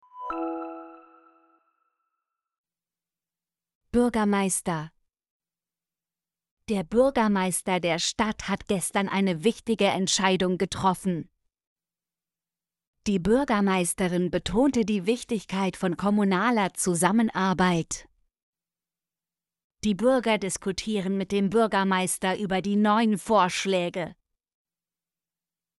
bürgermeister - Example Sentences & Pronunciation, German Frequency List